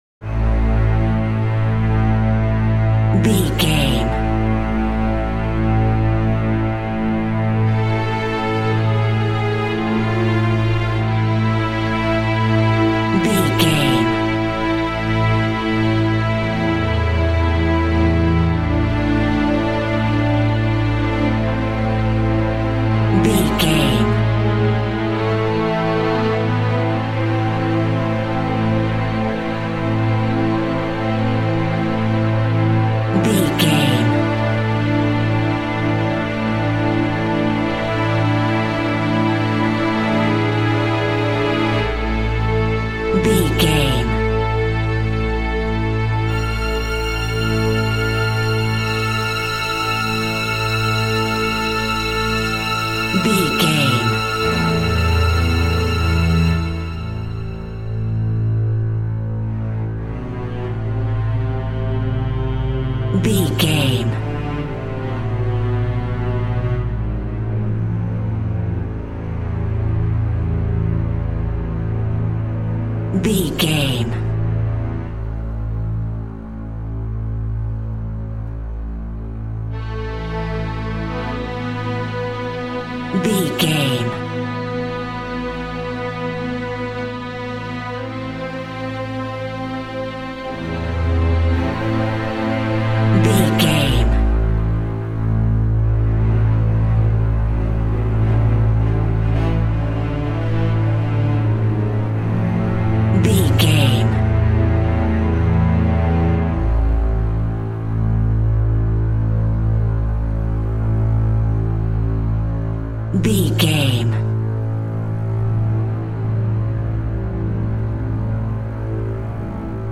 In-crescendo
Thriller
Aeolian/Minor
tension
ominous
dark
haunting
eerie
synth
ambience
pads